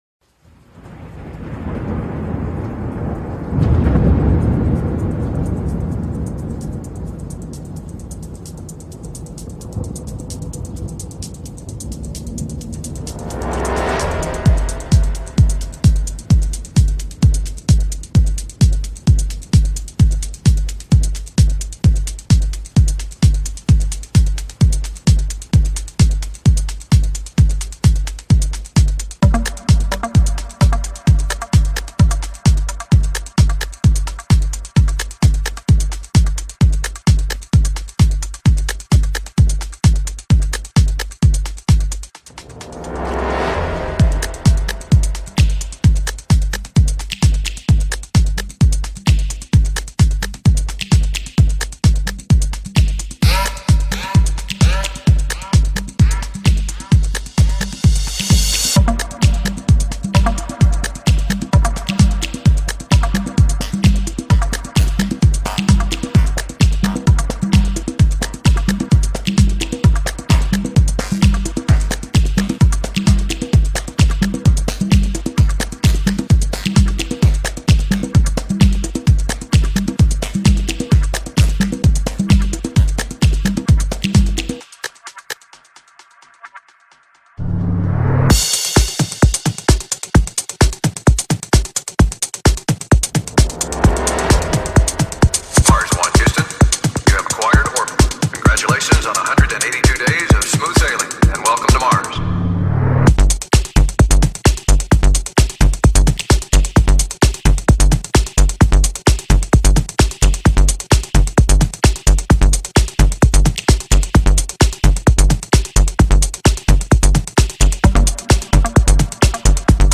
psytrance
música electrónica